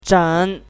chinese-voice - 汉字语音库
zeng3.mp3